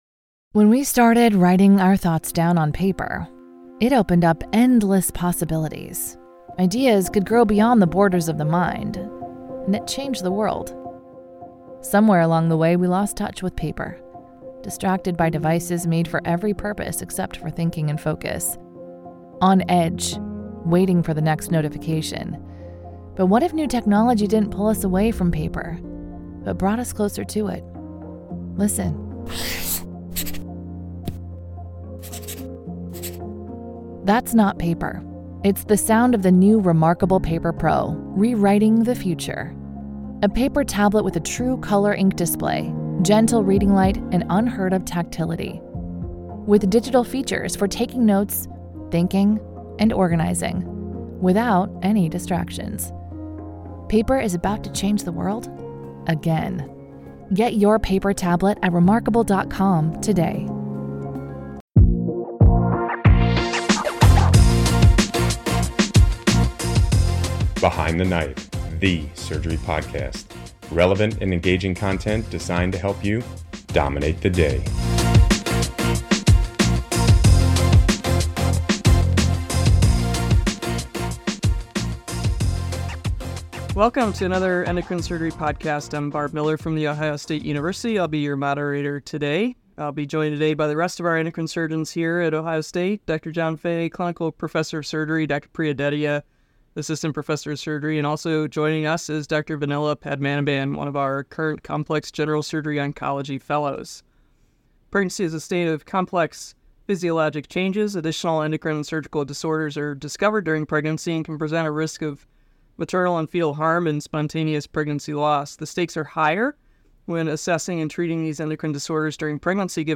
Hear about normal and abnormal thyroid and parathyroid physiology and treatment of patients with thyroid cancer. The group discusses several articles focusing on current guidelines from the American Thyroid Association as well as other key studies.